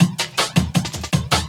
12 LOOP13 -R.wav